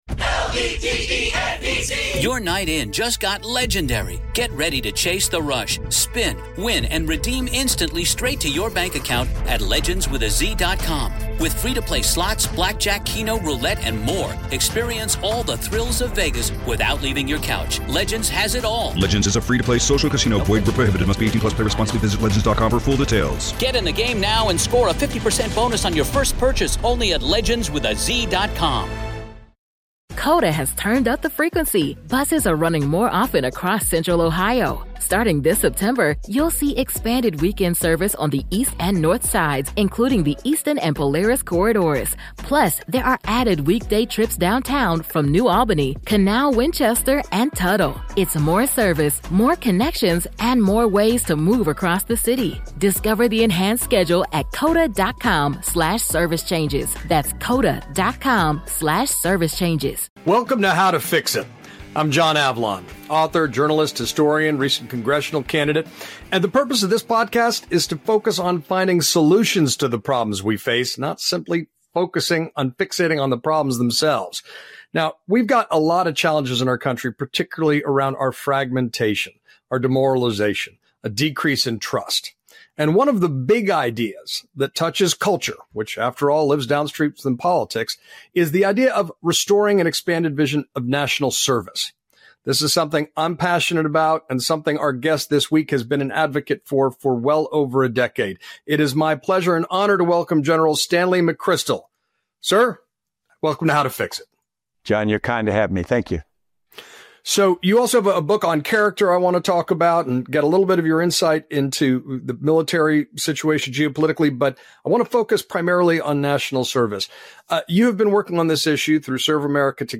In this episode of How to Fix It, General Stanley McChrystal joins John Avlon to reflect on the themes of his latest book on character and how personal integrity shapes public leadership.